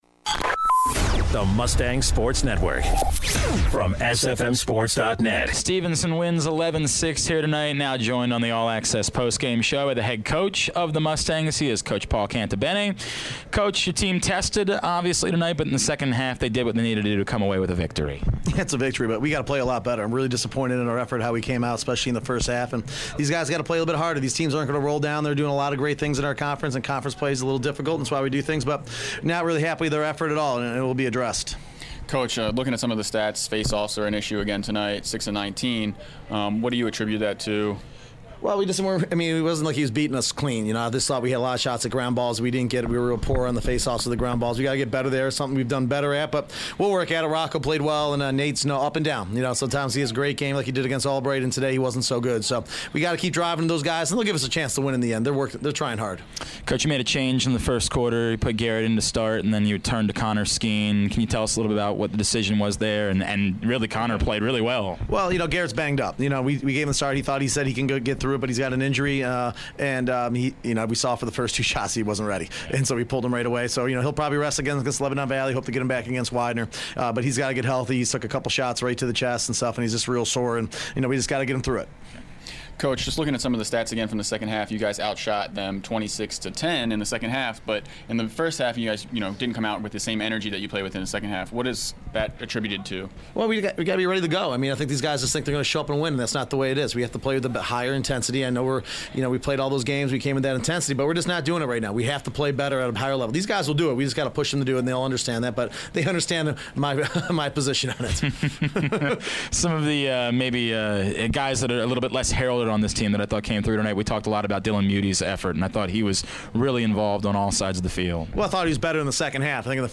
4/20/16: Stevenson Lacrosse Post Game Show